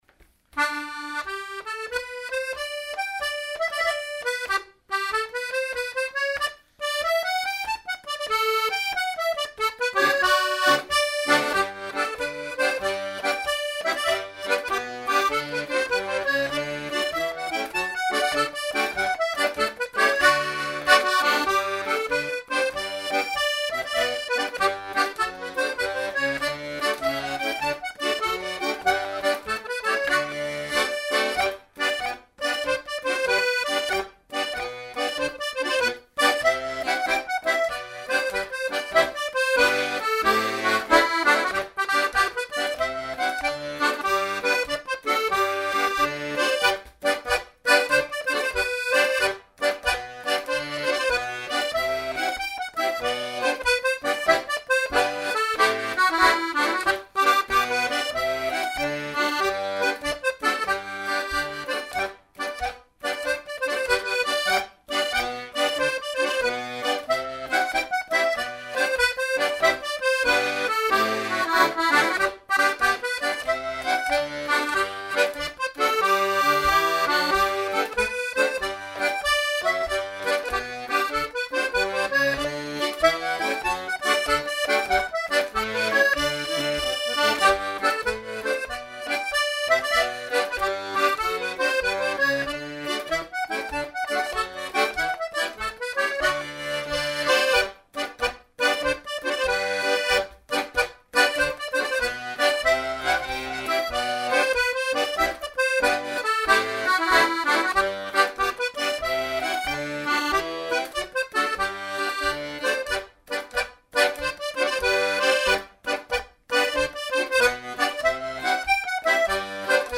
The mp3 files are low quality to economise on disc space.